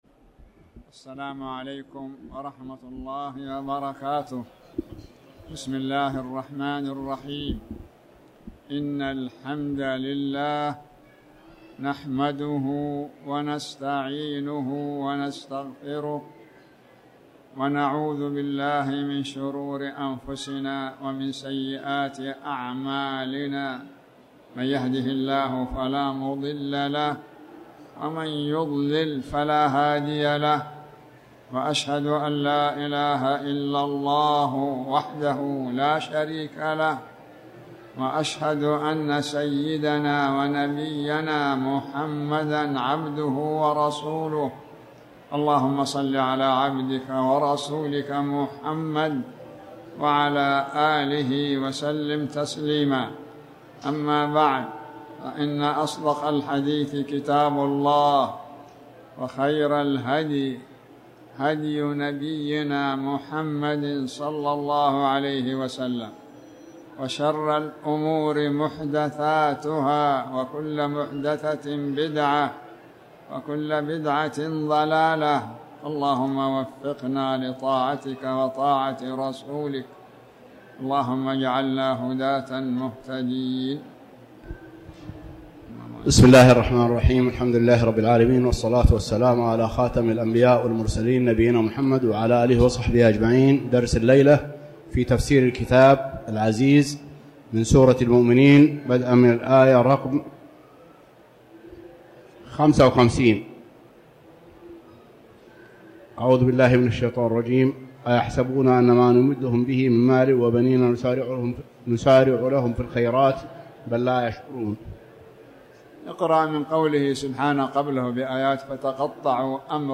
تاريخ النشر ٥ ذو القعدة ١٤٣٩ هـ المكان: المسجد الحرام الشيخ